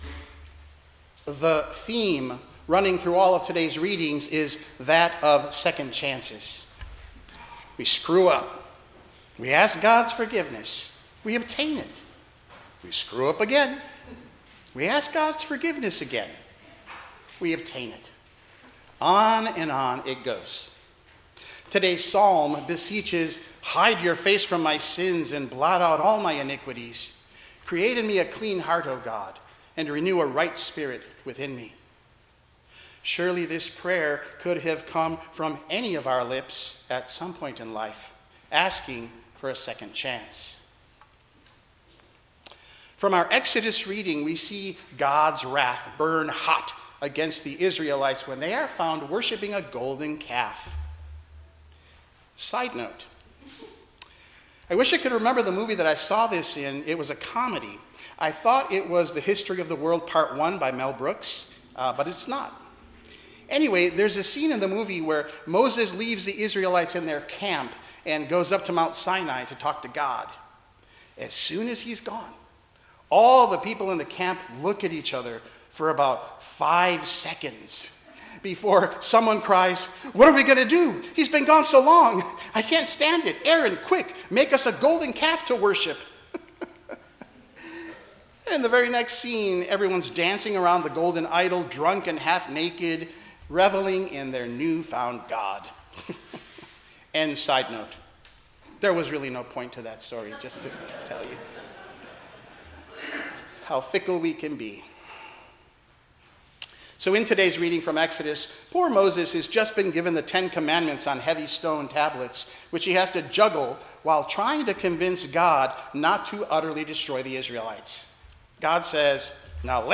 Passage: Exodus 32:7-14, Psalm 51:1-11, 1 Timothy 1:12-17, Luke 15:1-10 Service Type: 10:00 am Service